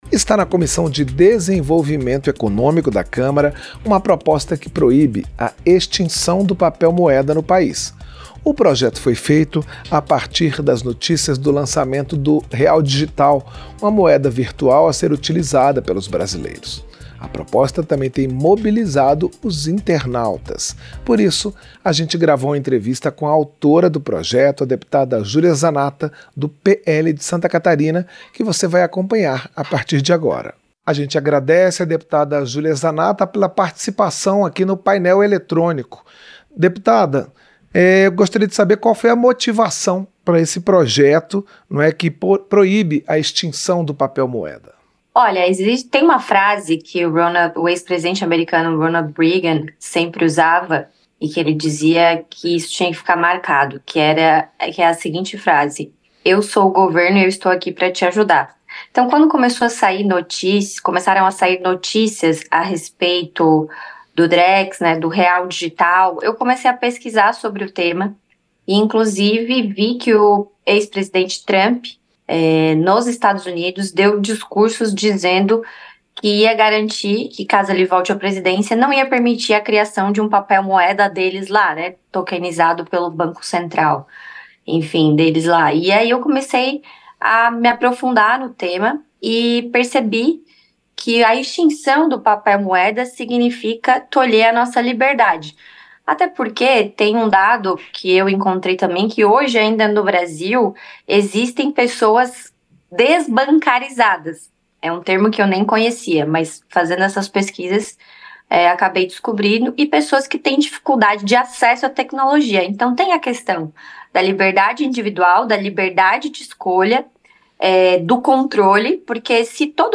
• Entrevista - Dep. Julia Zanatta (PL-SC)
Programa ao vivo com reportagens, entrevistas sobre temas relacionados à Câmara dos Deputados, e o que vai ser destaque durante a semana.